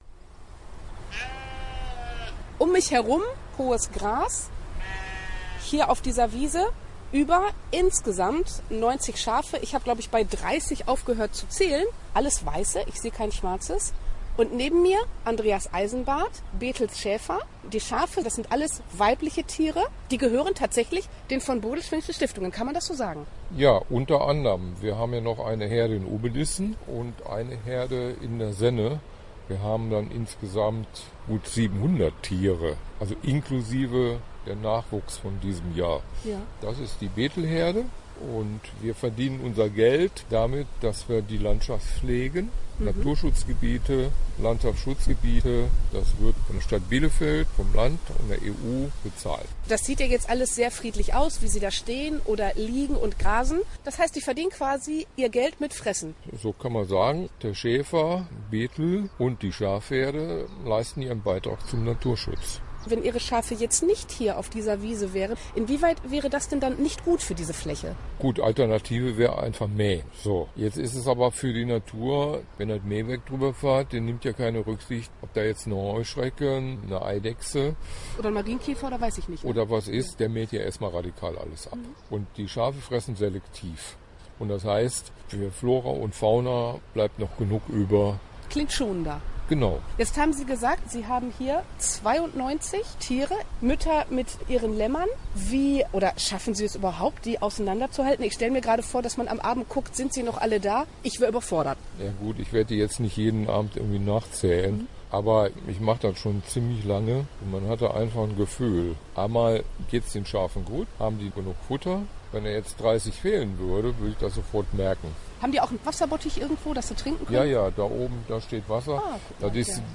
Podcast-Reportage-Bethelschafe-im-Sommer.mp3